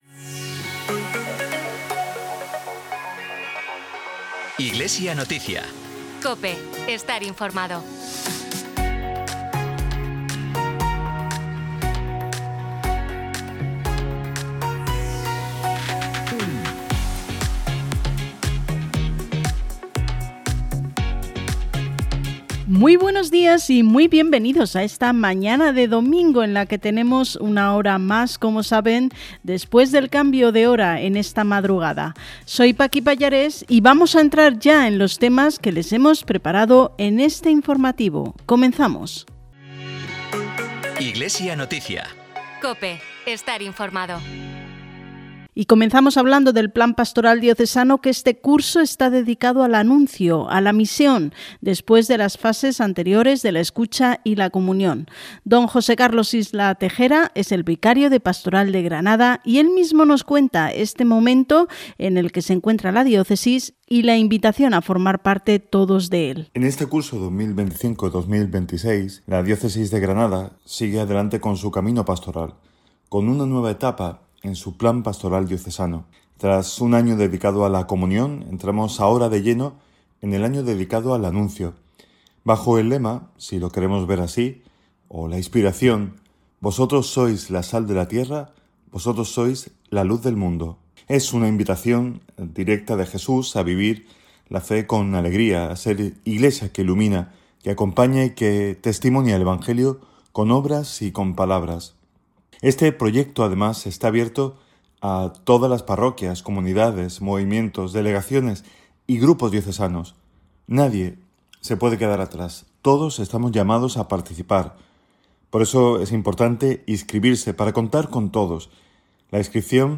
Emitido en COPE Granada y COPE Motril el 26 de octubre.